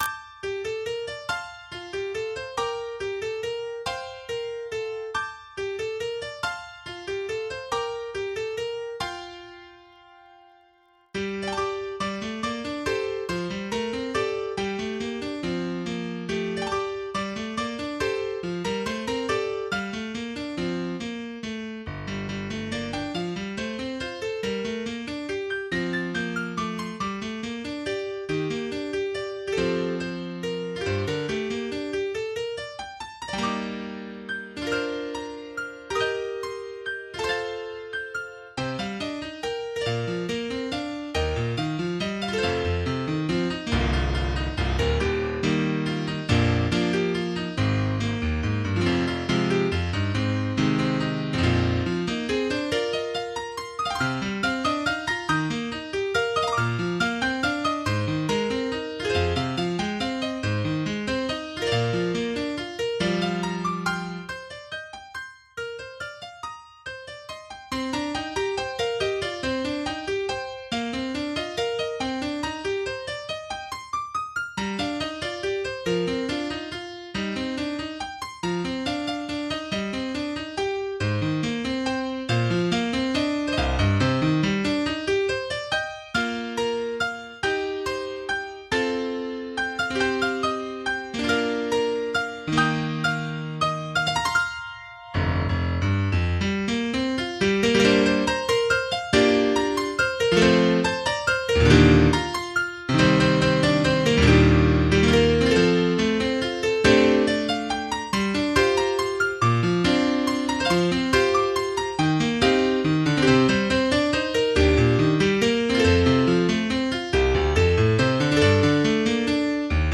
MIDI 16.3 KB MP3 (Converted) 3.69 MB MIDI-XML Sheet Music